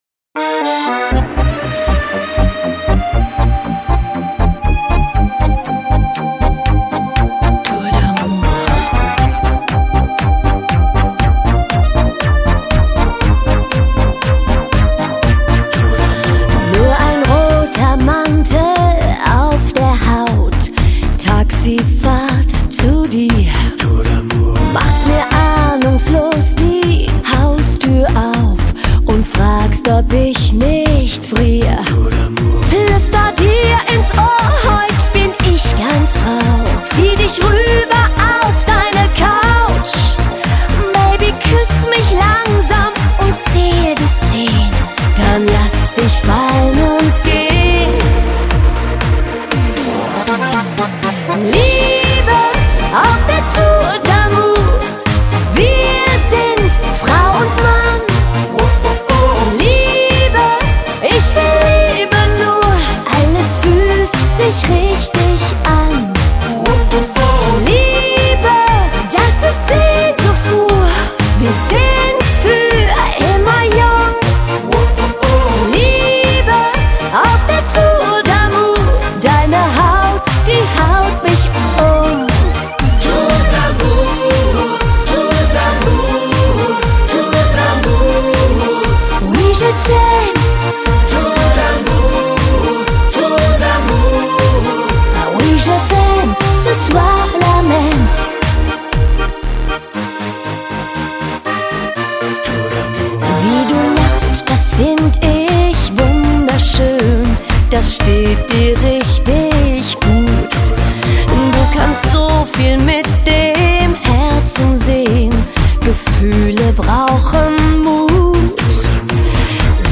Genre: German Pop